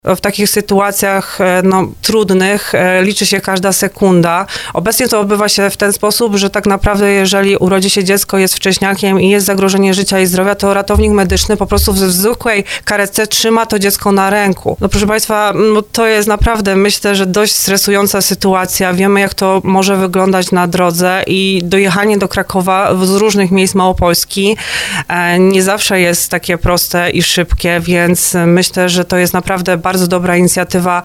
Marta Malec-Lech z zarządu województwa podkreśla, że dzięki temu urządzeniu można zapewnić noworodkom odpowiednie warunki podczas transportu, podtrzymując ich funkcje życiowe i zapewniając stały nadzór medyczny.